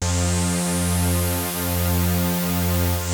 KORG F3  3.wav